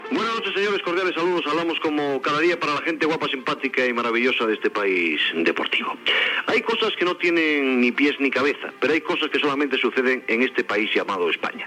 Salutació a l'inici del programa.
Esportiu